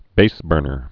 (bāsbûrnər)